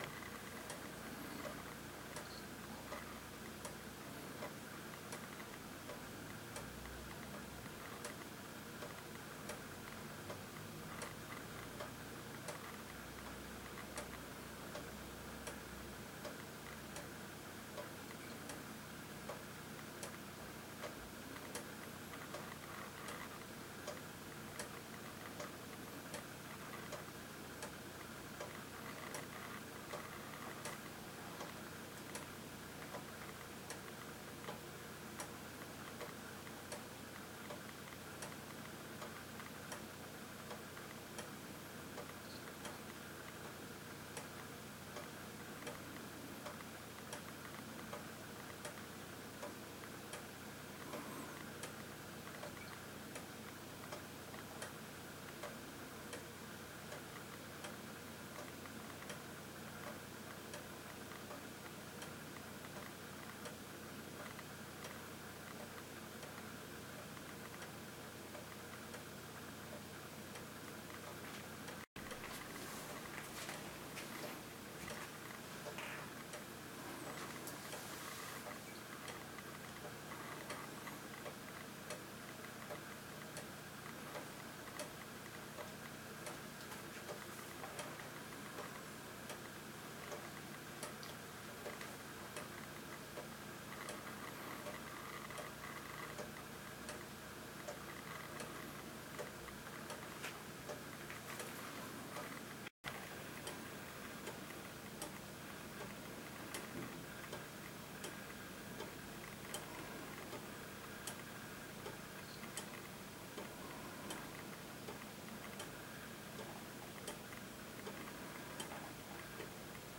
Clock_II.ogg